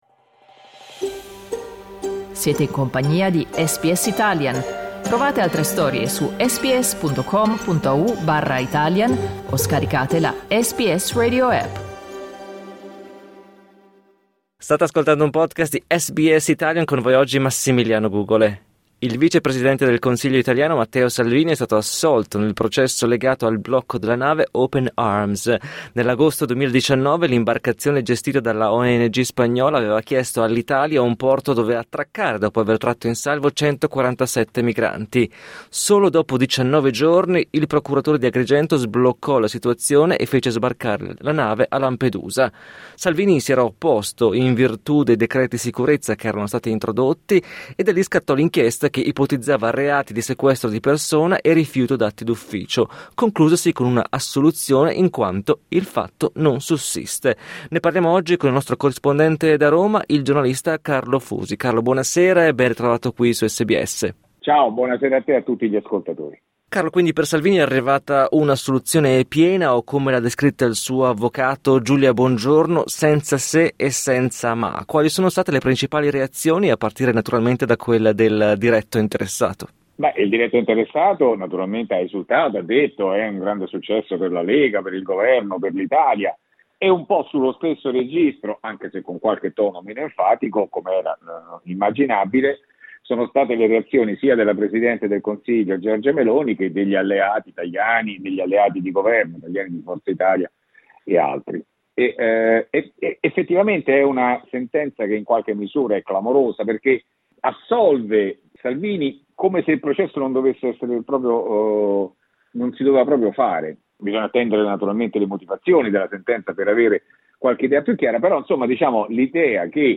l'analisi del giornalista